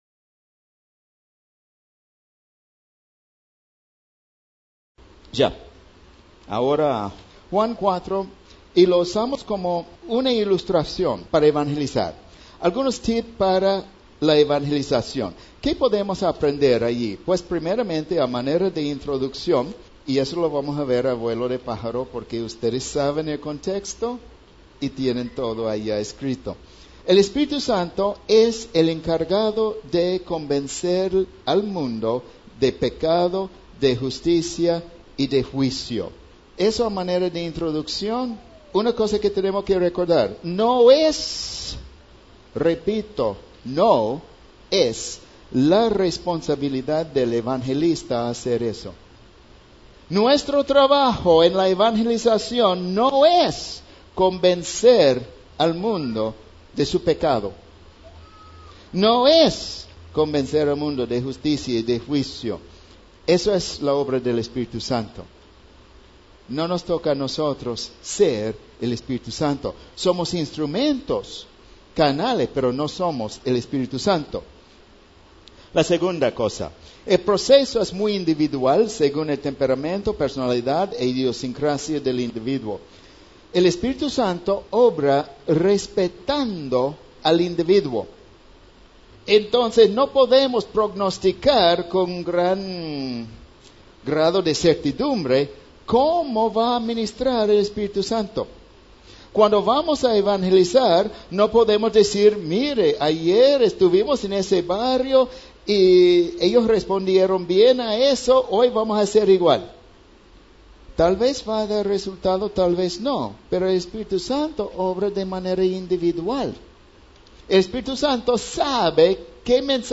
Lecciones para la evangelizaci�n, basadas en Juan 4